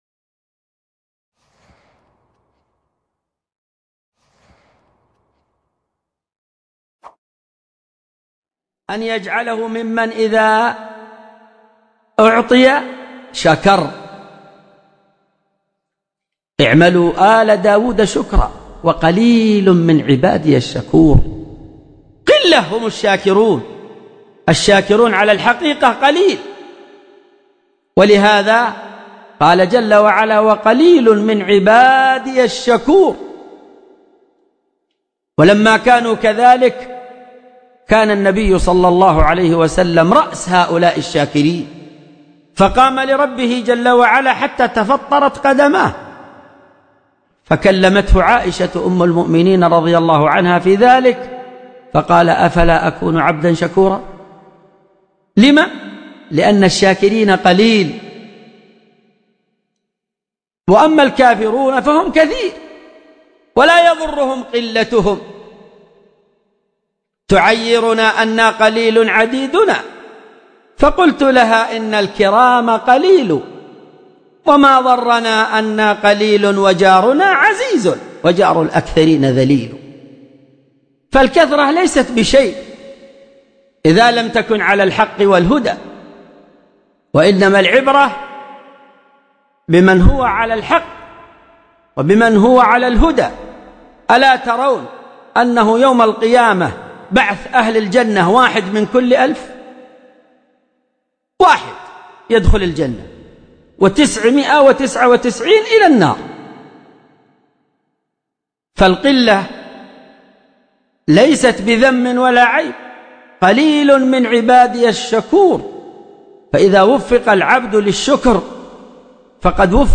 من مواعظ أهل العلم
Format: MP3 Mono 22kHz 64Kbps (CBR)